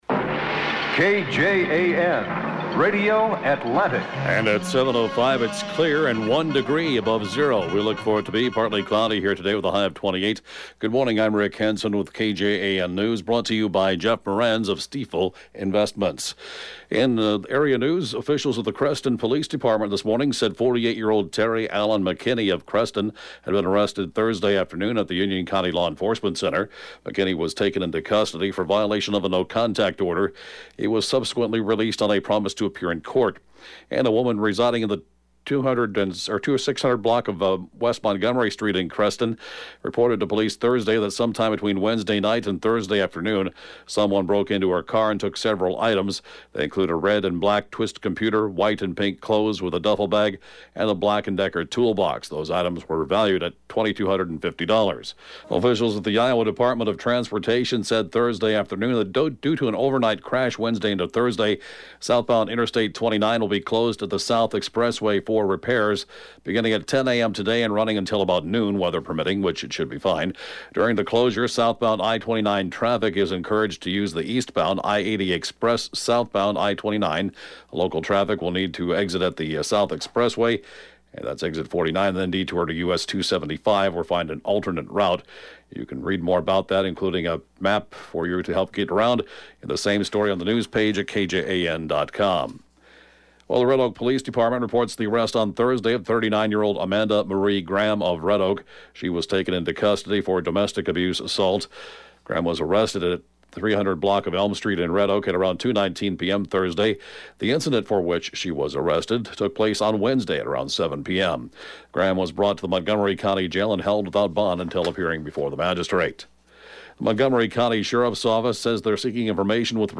(Podcast) KJAN Morning News & Funeral report, 2/14/20